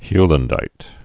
(hylən-dīt)